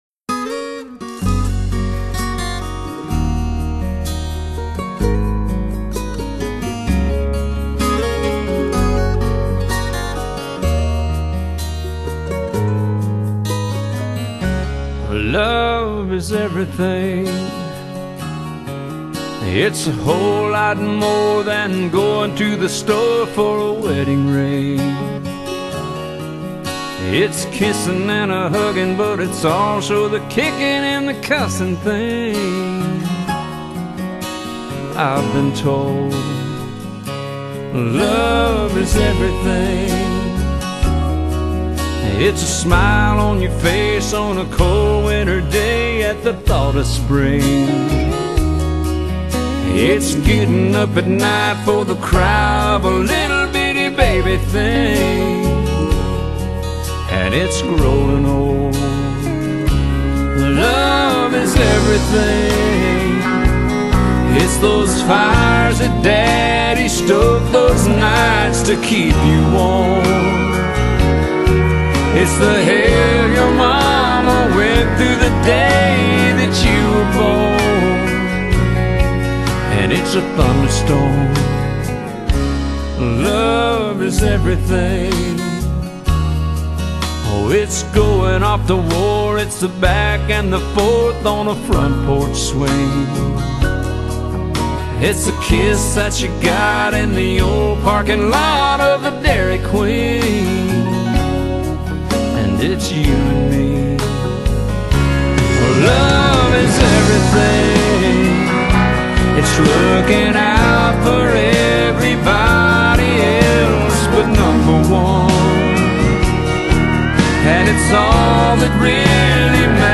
鄉村天王